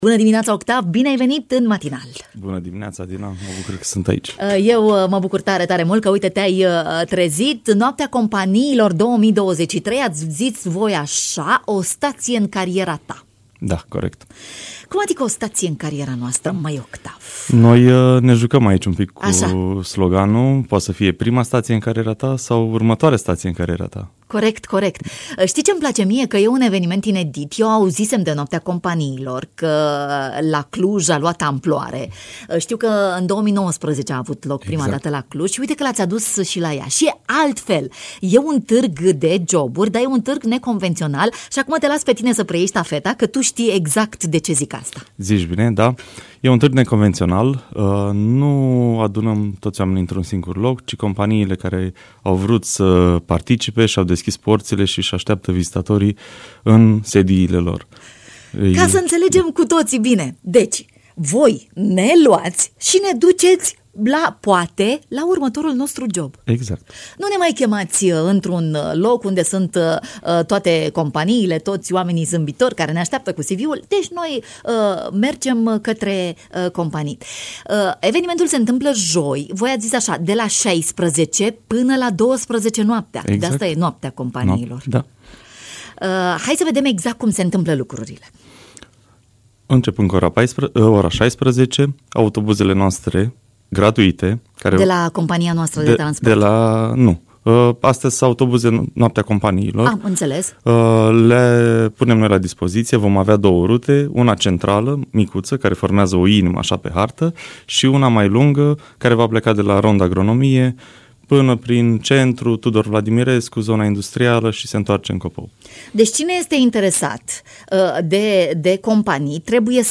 Radio Iaşi – Cel mai ascultat radio regional - știri, muzică și evenimente